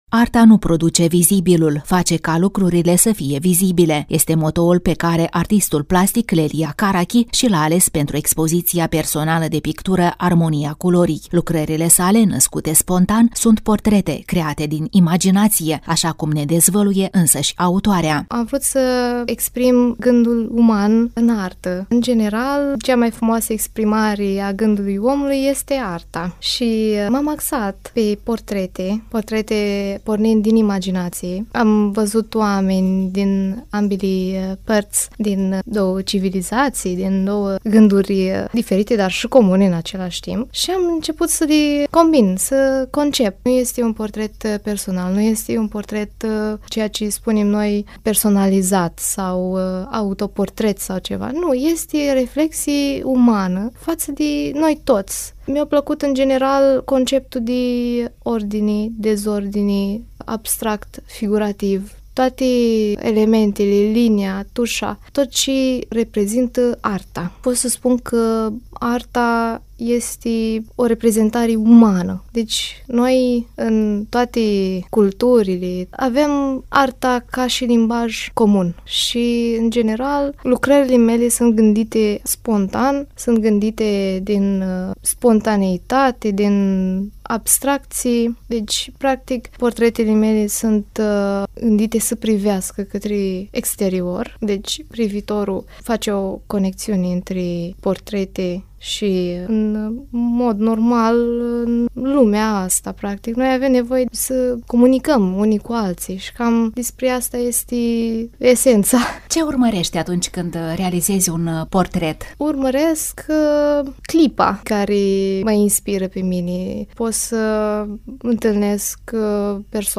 Reportaj cultural